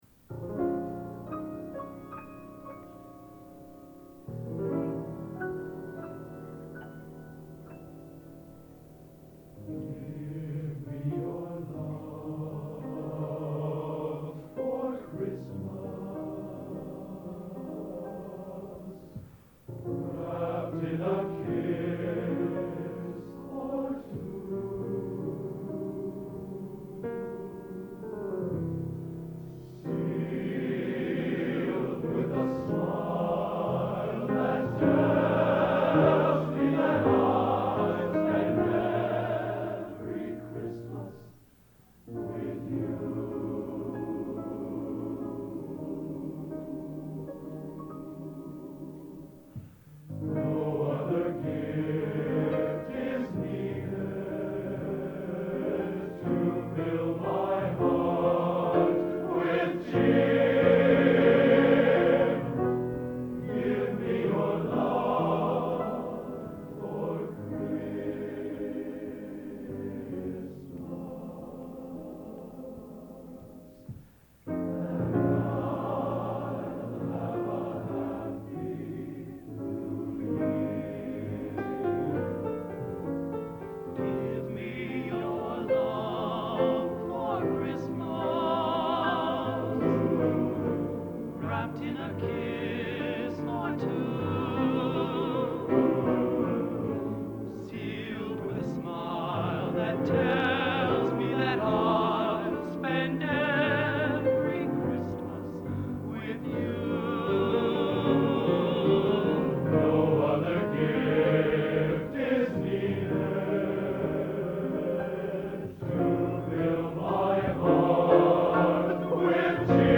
Genre: | Type: Christmas Show |